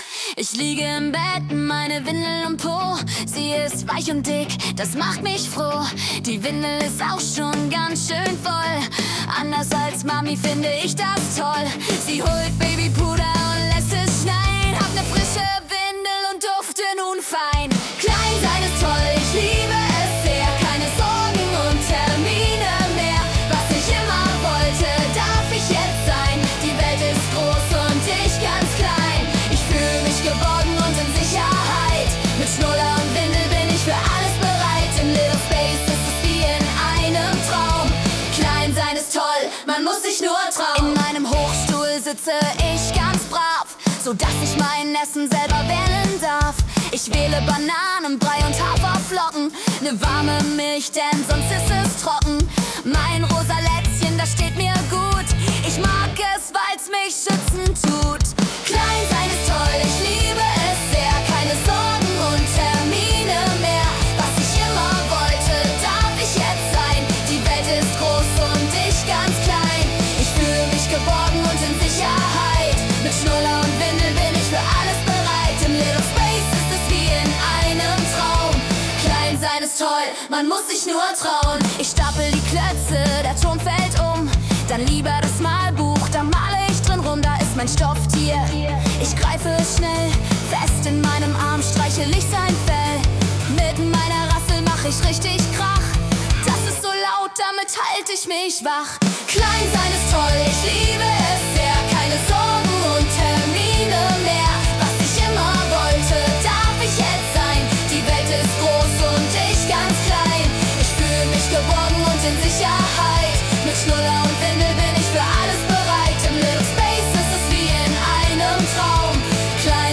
ist ein liebevoller, optimistischer Song